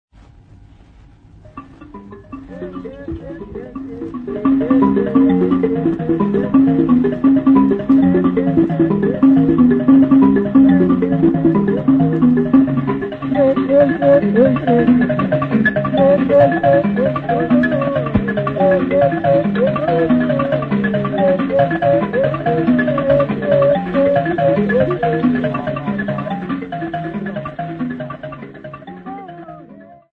Folk Music
Field recordings
Africa Mozambique city not specified f-mz
sound recording-musical
Indigenous music